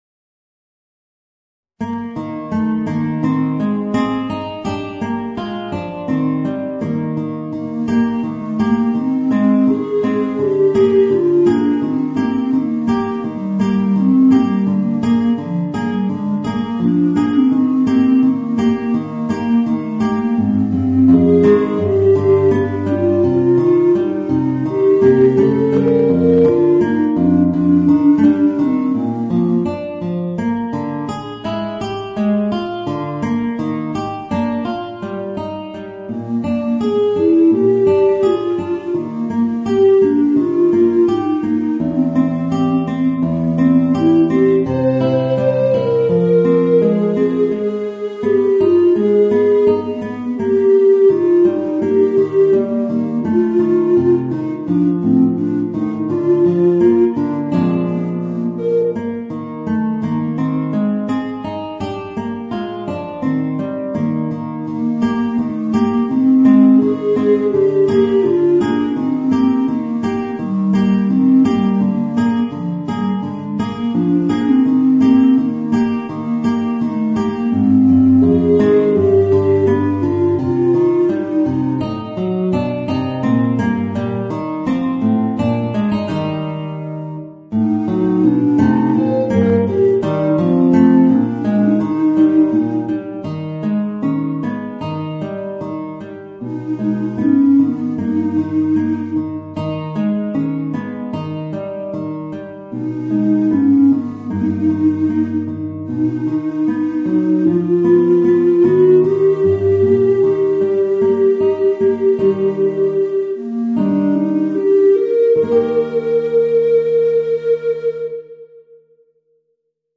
Voicing: Guitar and Bass Recorder